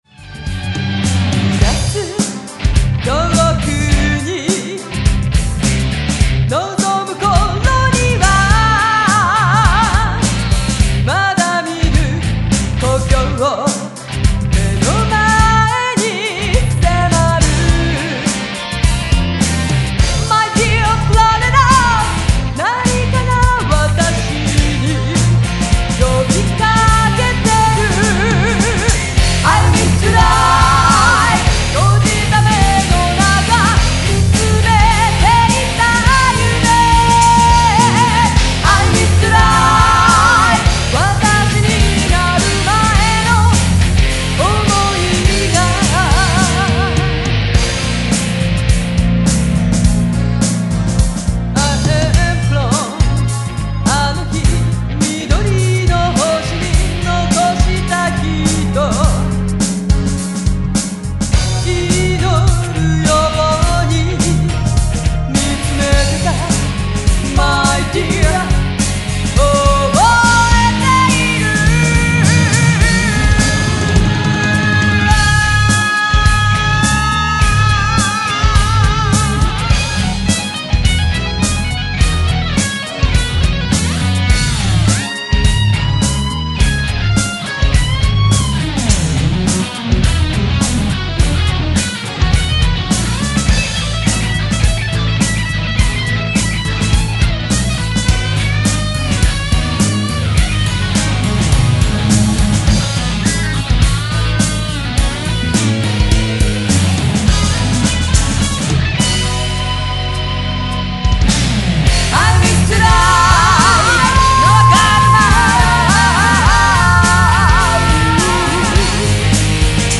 (Alternative Metal/Hip Hop)